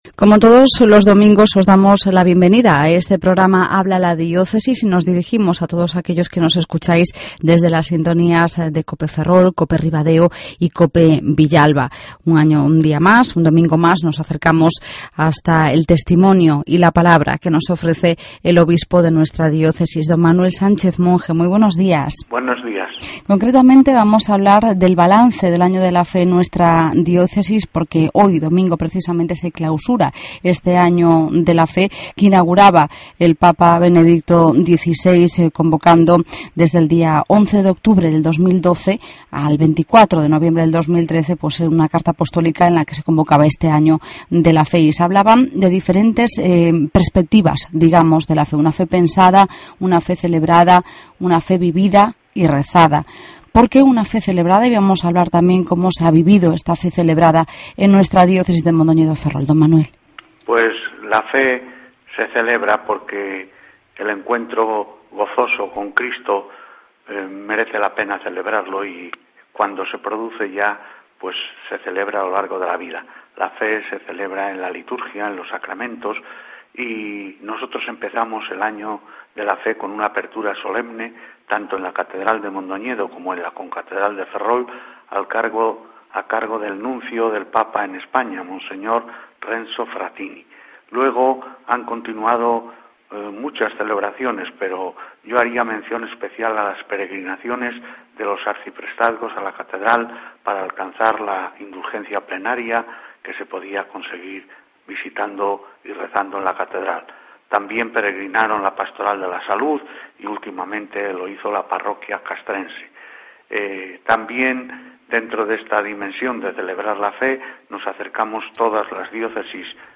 El Obispo Don Manuel Sánchez Monge hace balance del Año de la Fe en nuestra diócesis de Mondoñedo-Ferrol.